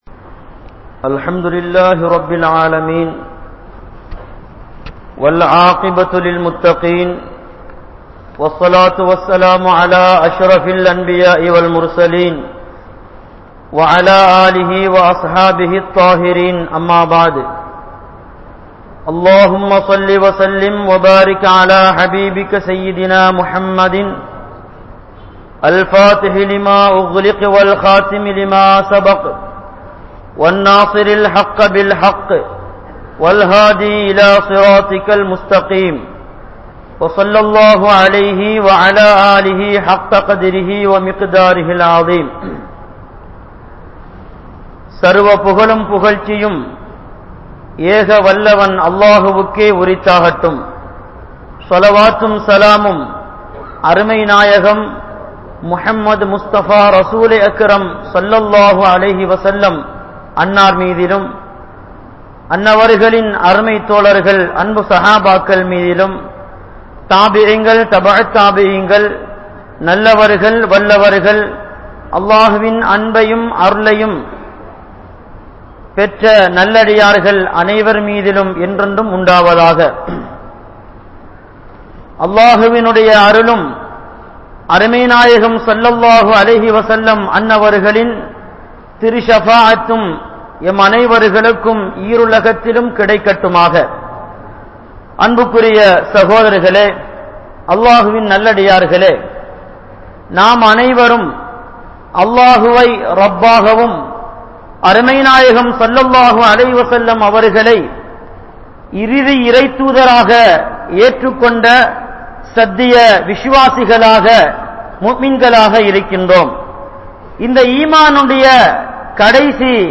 Aatsiyaalarinaal Soathanai Varak Kaaranam Enna? (ஆட்சியாளரினால் சோதனை வரக் காரணம் என்ன?) | Audio Bayans | All Ceylon Muslim Youth Community | Addalaichenai
Grand Jumua Masjith